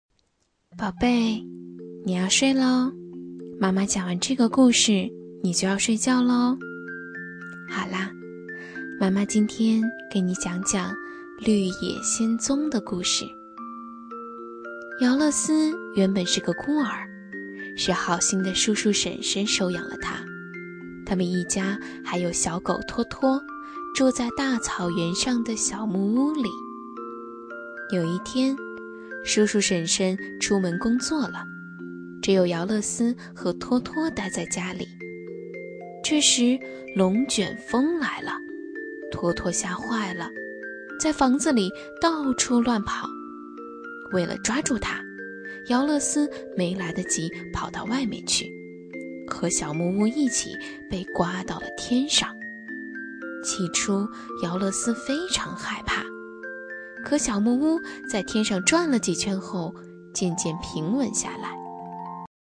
【女58号课件】 幼儿睡前故事（温柔婉约）